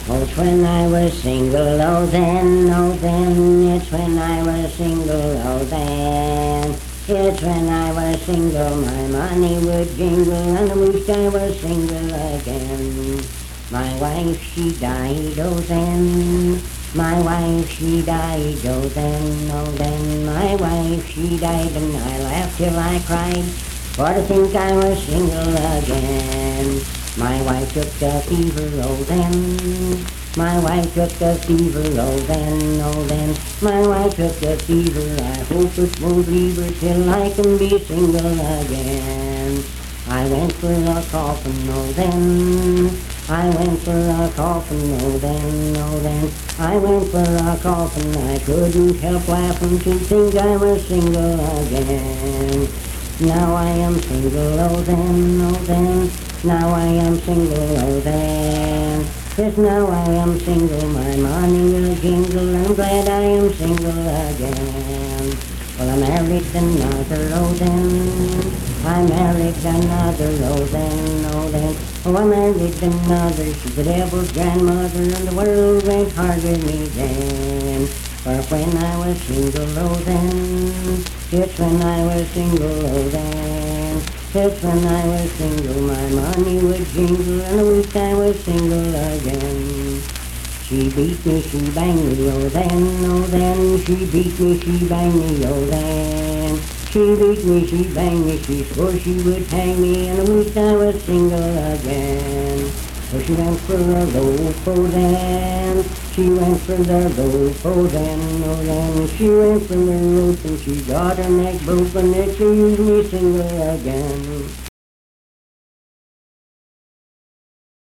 Unaccompanied vocal music
Performed in Sandyville, Jackson County, WV.
Voice (sung)